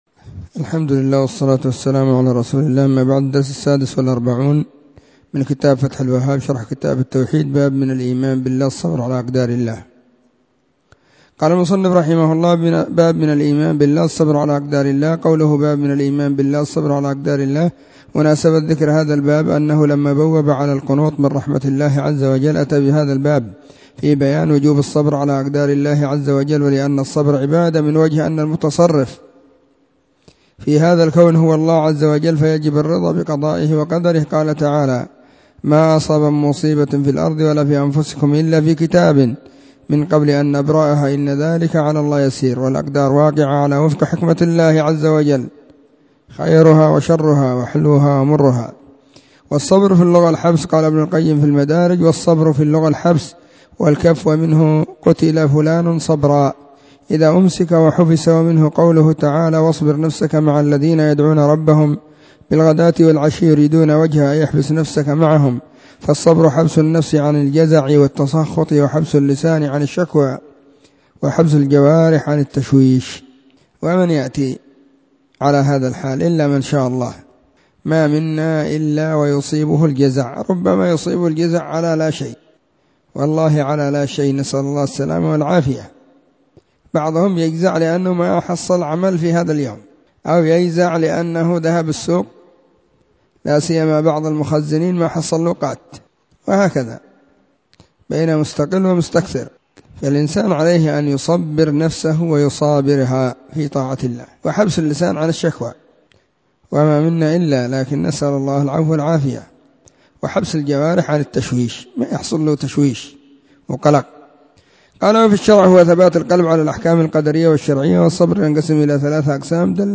📢 مسجد الصحابة بالغيضة, المهرة، اليمن حرسها الله.🗓الثلاثاء 12/صفر/ 1442 هجرية